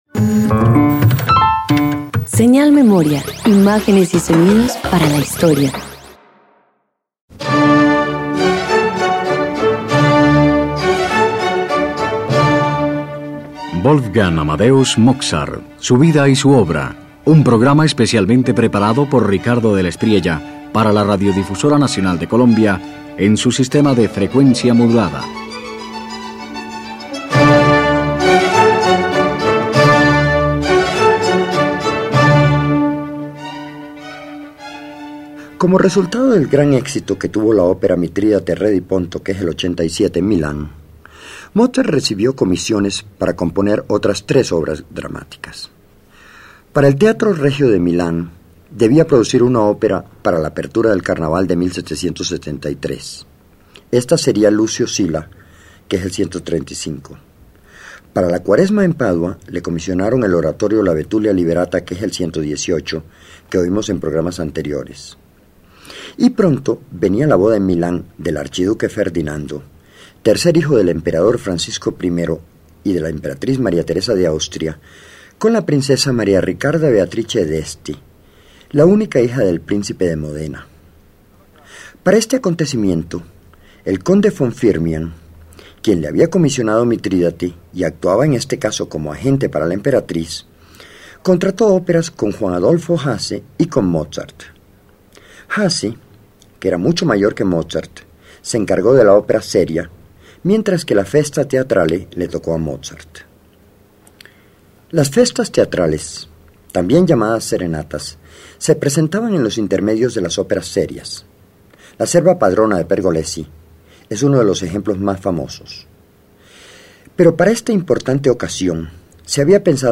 Milán se viste de alegoría y Mozart, con quince años, celebra la boda imperial con una serenata mitológica. “Ascanio in Alba” abre con coros, danzas y una Venus radiante: la divinidad desciende mientras la orquesta pinta la luz del amanecer.